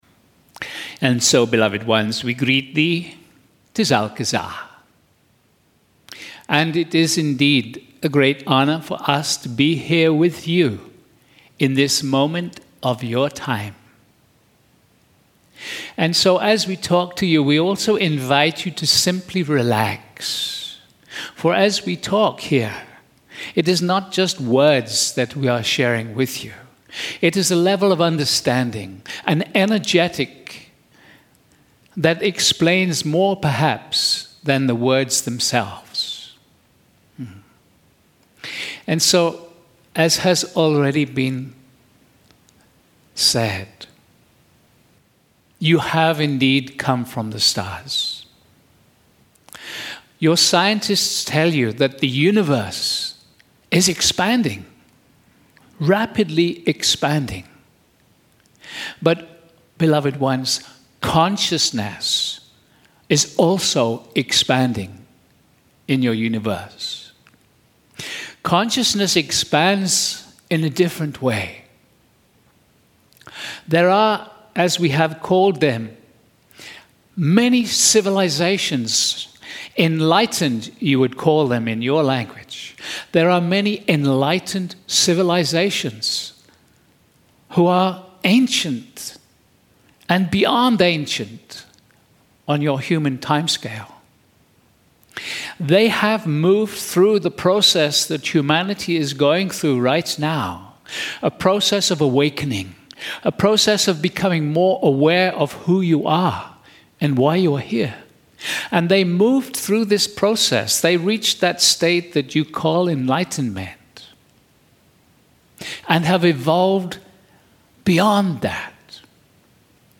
MULTI-CHANNELLING